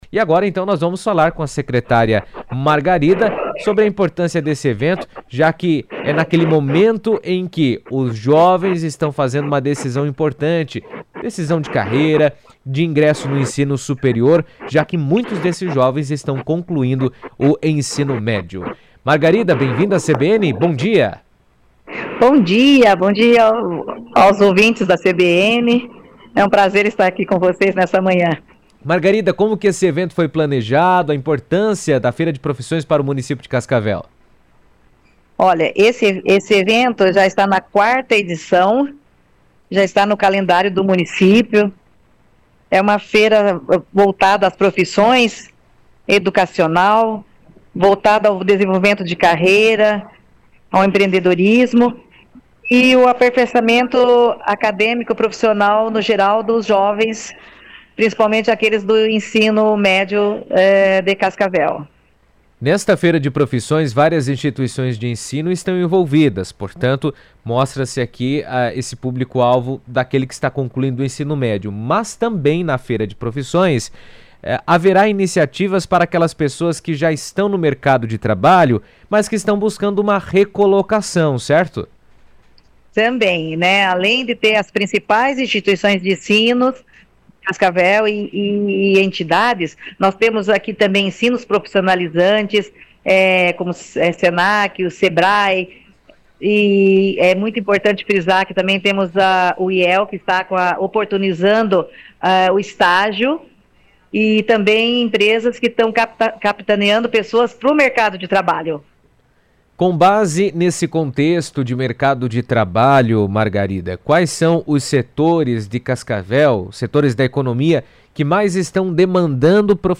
Margarida Carneiro, secretária de Desenvolvimento Econômico, comentou sobre a feira em entrevista à CBN, destacando a importância